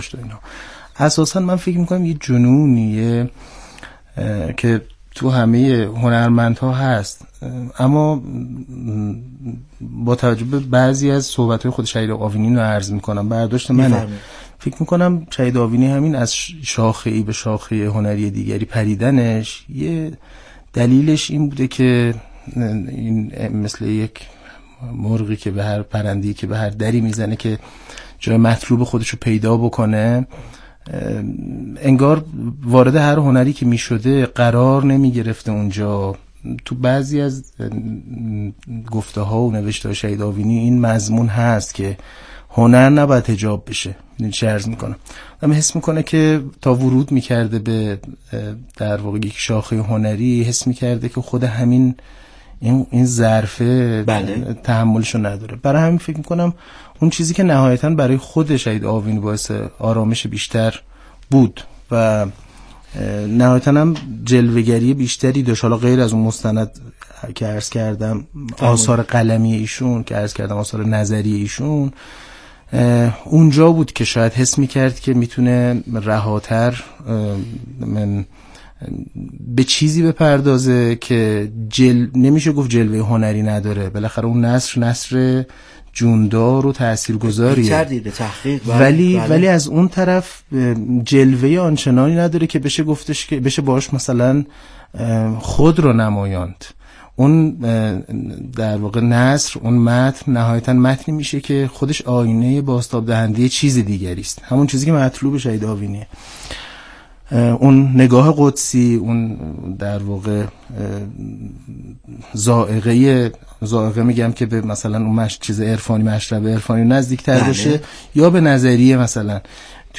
میزگرد تعاملی ایکنا